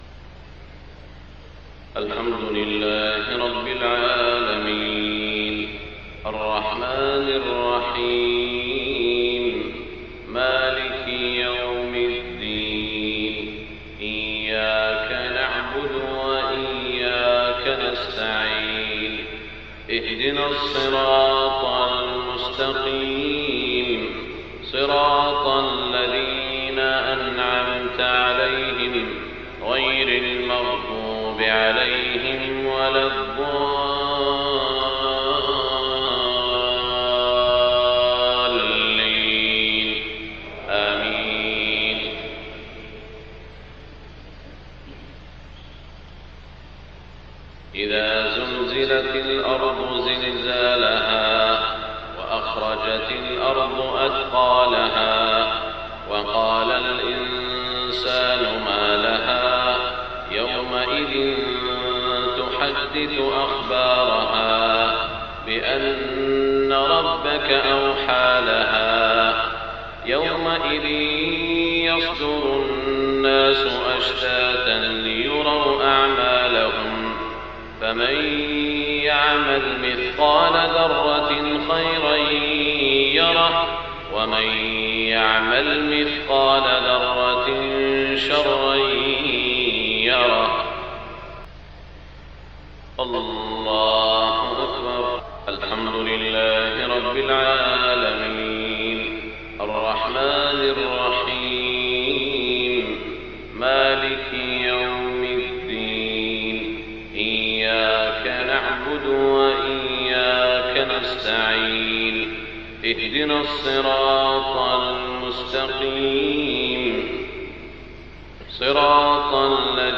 صلاة المغرب 17 شوال 1427هـ سورتي الزلزلة و الناس > 1427 🕋 > الفروض - تلاوات الحرمين